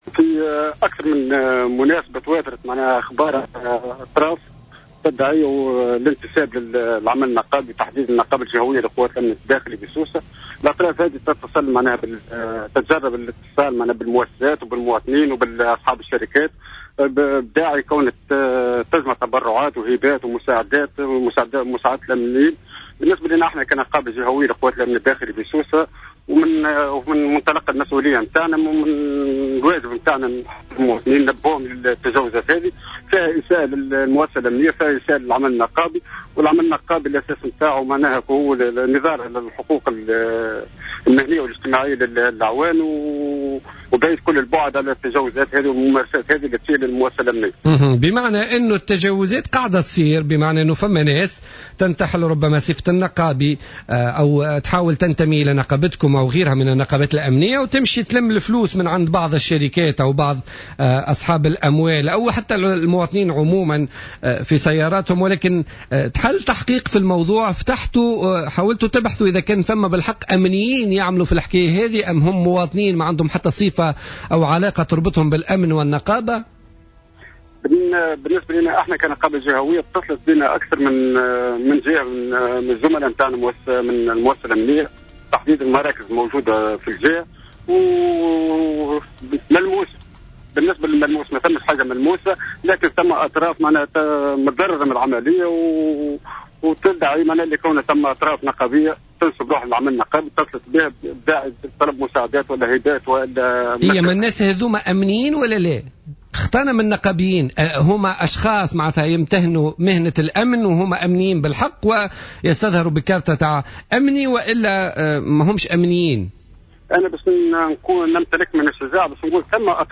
مداخلة له اليوم الاربعاء في برنامج "بوليتيكا"